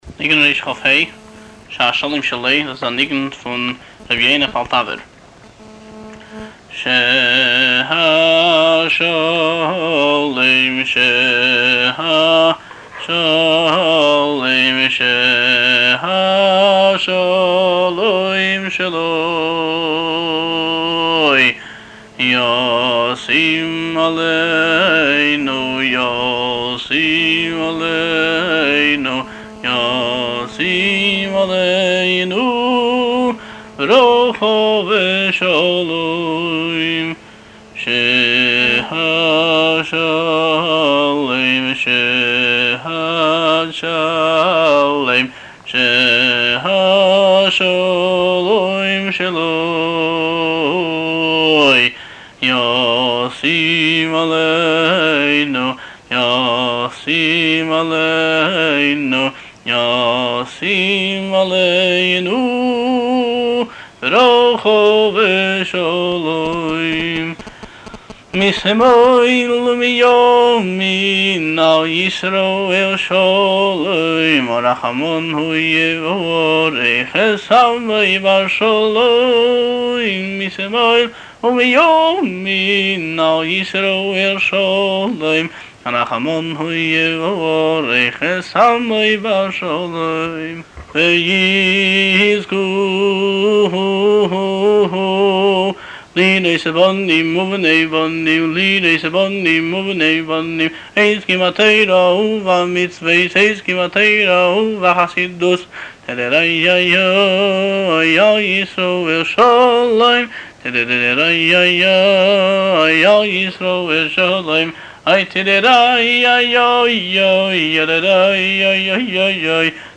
הניגון
הבעל-מנגן